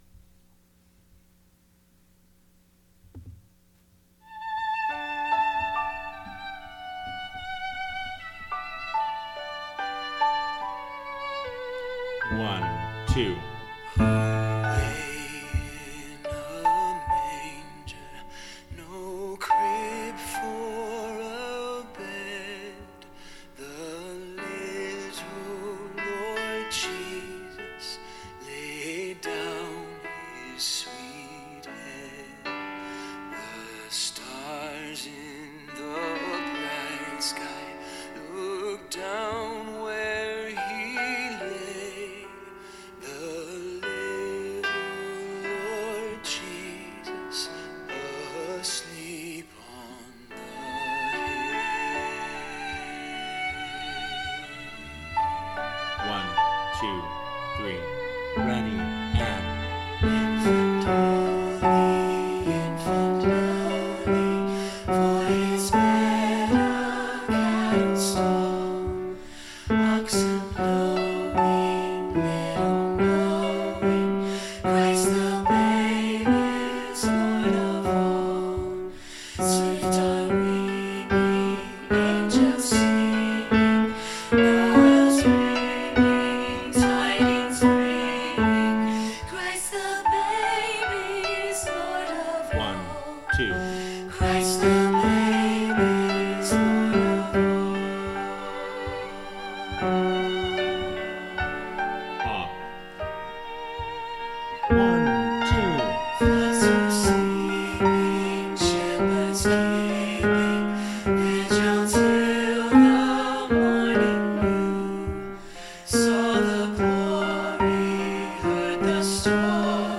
Mp3 Practice and sing along tracks
Away-In-A-Manger-with-Infant-Tenor.mp3